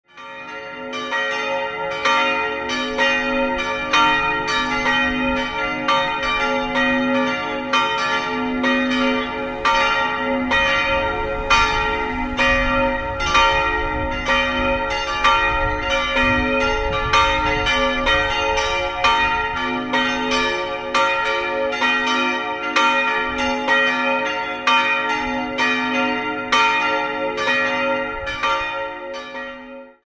Sie ist eine der schönsten barocken Landkirchen im Umkreis und wurde in der ersten Hälfte des 18. Jahrhunderts errichtet. In den Jahren 1908/09 erfolgte eine Erweiterung. 3-stimmiges B-Dur-Geläute: b'-d''-f'' Die große Glocke wurde im Jahr 1875 von Josef Anton Spannagl in Regensburg gegossen, die beiden kleineren stammen aus der Gießerei Karl Hamm (Regensburg) und wurden 1928 hergestellt.